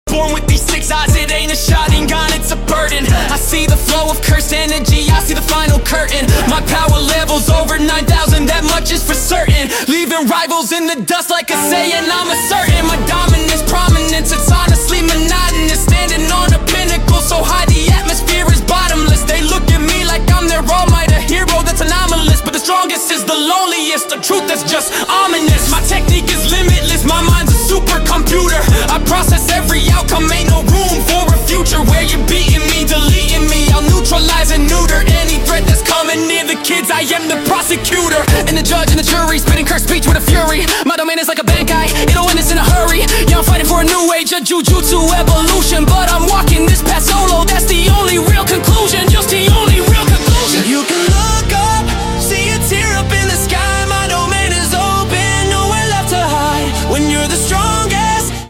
UK Drill